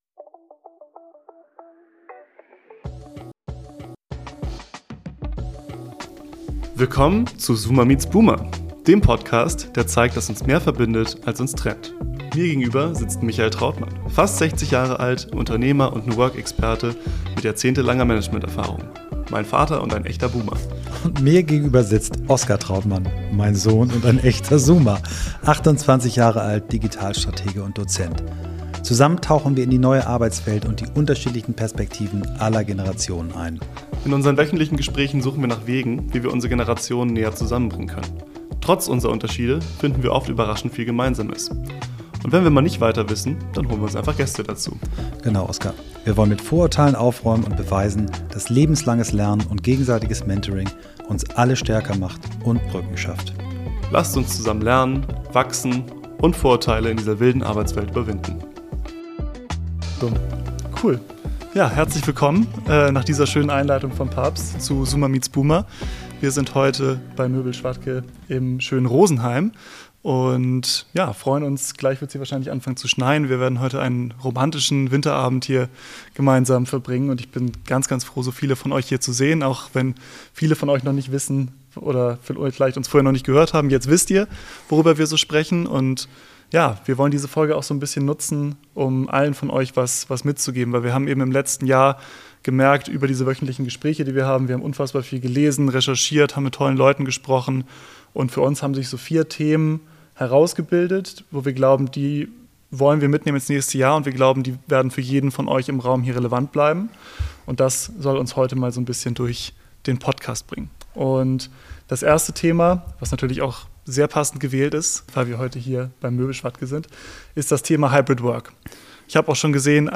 Beschreibung vor 1 Jahr Der Dezember naht, und bevor wir alle ins Weihnachtsplätzchen-Koma gleiten, haben wir noch ich eine ganz besondere Folge unseres Podcasts Zoomer Meets Boomer für euch: Live aus Rosenheim, bei Schwadke Büroeinrichtungen, powered by Vitra, haben wir gemeinsam mit einem tollen Publikum über unsere Learnings aus 2024 gesprochen und einen Blick in die Arbeitswelt von morgen geworfen. Wir haben die Highlights des Jahres zusammengetragen und diskutiert, wie Hybrid Work, Leadership, KI und Reverse Mentoring unser Arbeitsleben prägen.